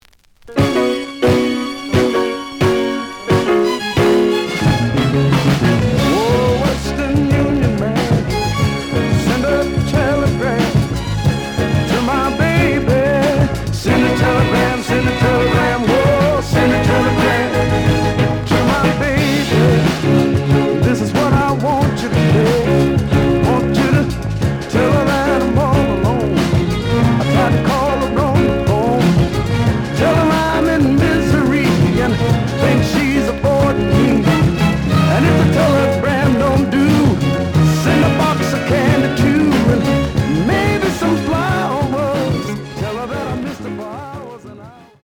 The audio sample is recorded from the actual item.
●Genre: Soul, 60's Soul
Some noise on A side. B side plays good.)